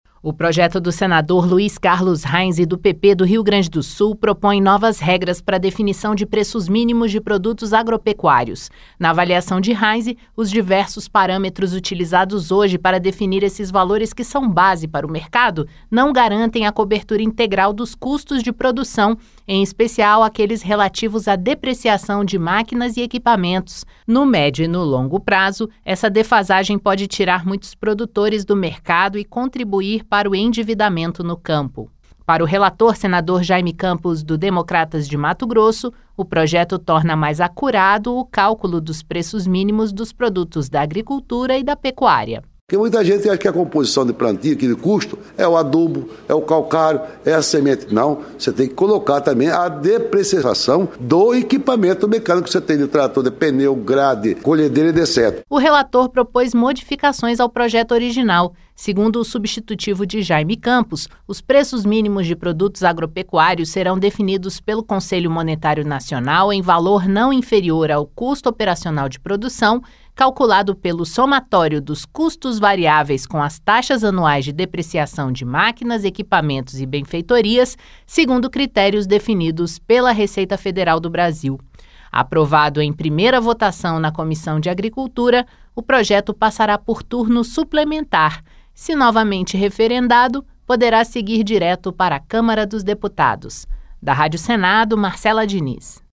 Rádio Senado Notícias